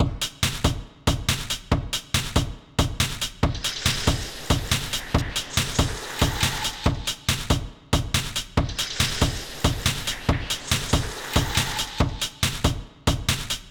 bags on gravel gave an excellent source for a crunchy snare drum, and dropping a somewhat denser package on the deck lent itself to a kick drum. the recording also picked up some breathy childish sniggering which i thought could pass as a 909 hat.
i chopped up the sounds and did a bit of EQ and envelope shaping in sound forge, and then loaded them up into a sampler in my DAW. i squirted the gravel footsteps into os’s xfadelooper and used cyclone to trigger the percussion sounds. after a bit of mucking around, this is the